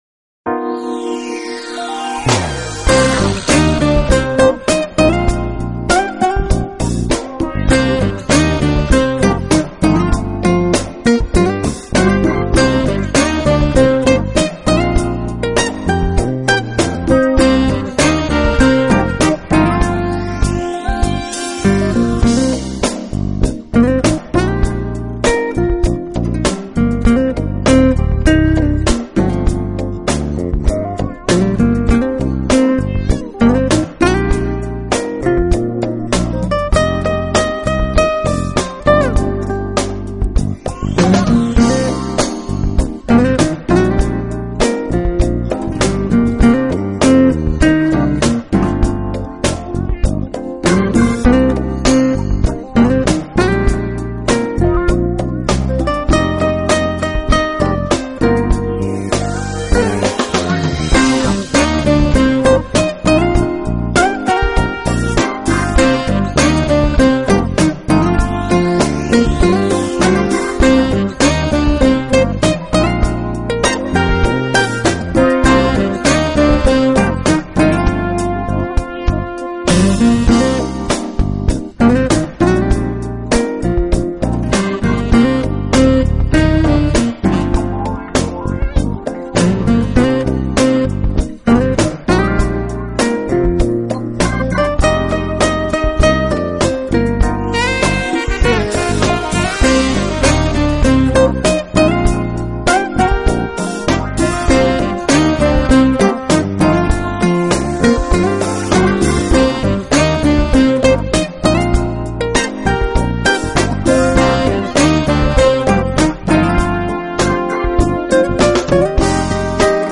音乐类型：Smooth Jazz
guitar
soprano saxophone
piano, electric piano, keyboards
electric bass
drums
percussion